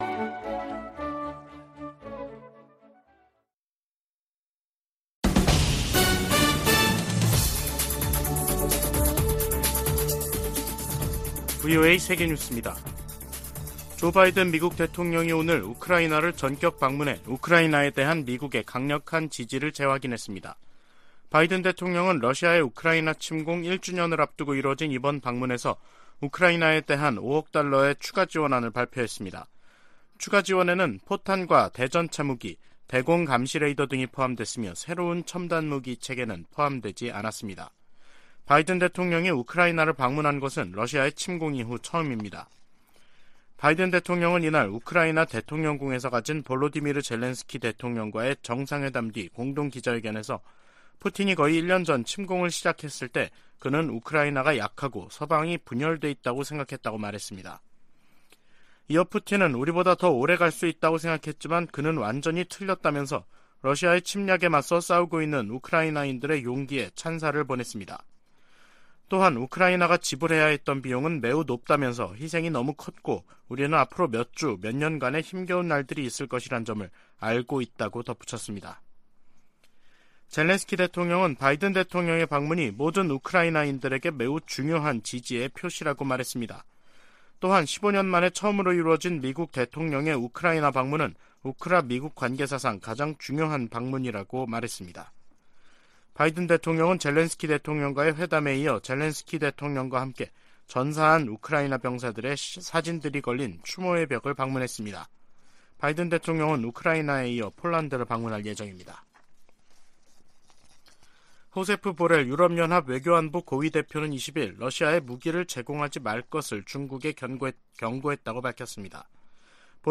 VOA 한국어 간판 뉴스 프로그램 '뉴스 투데이', 2023년 2월 20일 3부 방송입니다. 북한이 ‘화성-15형’ 대륙간탄도미사일(ICBM)을 쏜 지 이틀 만에 평안남도 숙천 일대에서 동해상으로 초대형 방사포를 발사했습니다. 미국과 한국, 일본 외교장관이 긴급 회동을 갖고 북한의 ICBM 발사를 규탄하면서 국제사회의 효과적인 대북제재 시행을 촉구했습니다. 한국 정부는 북한의 ICBM 발사 등에 대해 추가 독자 제재를 단행했습니다.